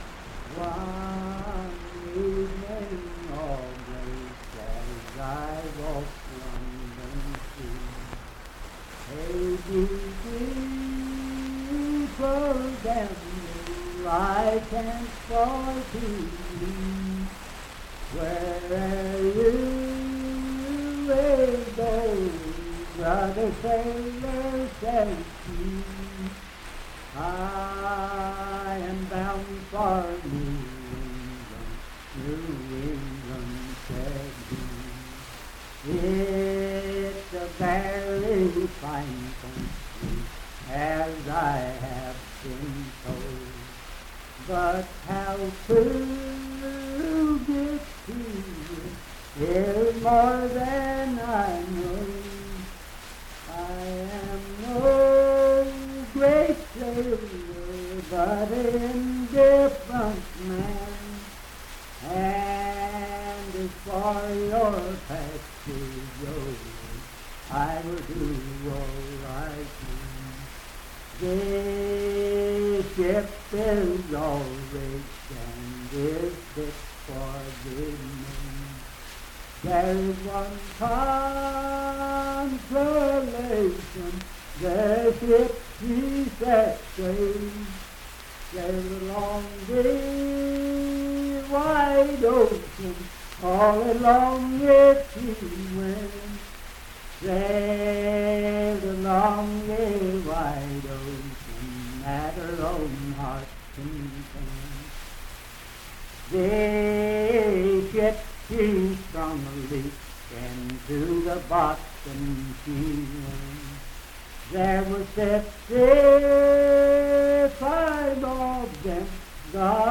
Unaccompanied vocal music and folktales
Voice (sung)
Wood County (W. Va.), Parkersburg (W. Va.)